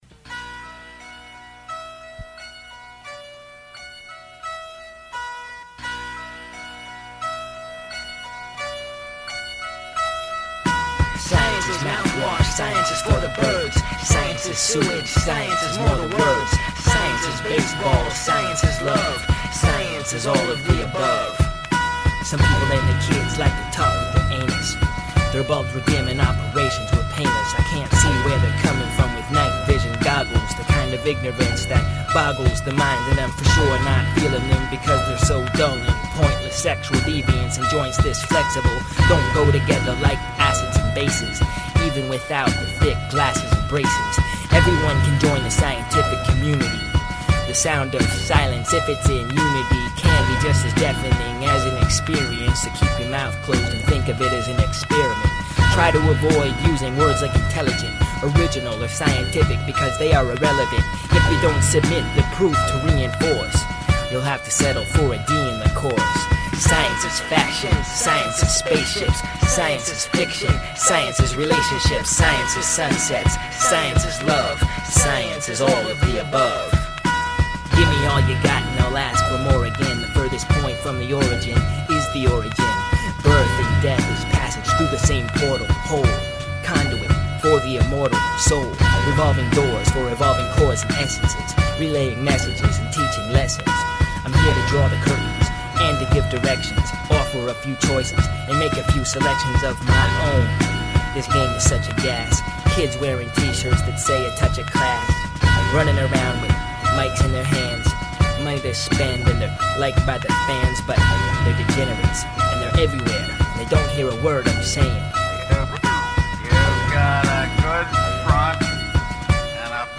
it is really poorly produced and mixed